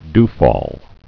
(dfôl, dy-)